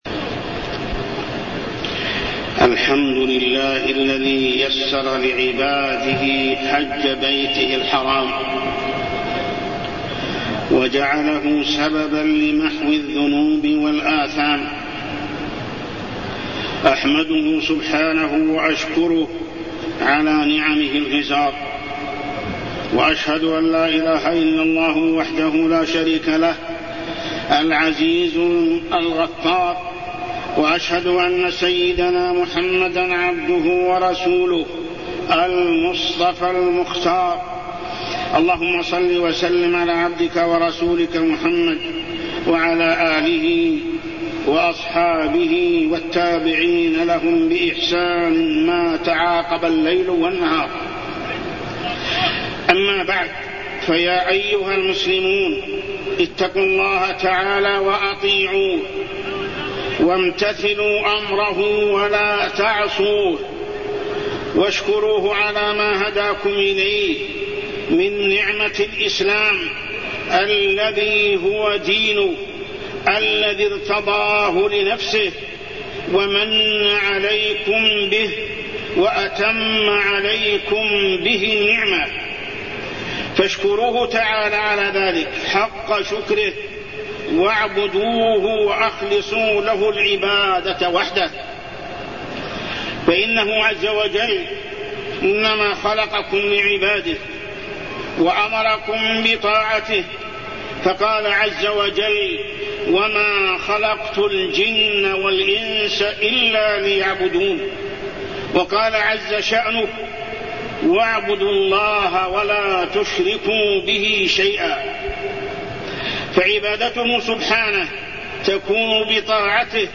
تاريخ النشر ٤ ذو الحجة ١٤٢٠ هـ المكان: المسجد الحرام الشيخ: محمد بن عبد الله السبيل محمد بن عبد الله السبيل صفة حج النبي صلى الله عليه وسلم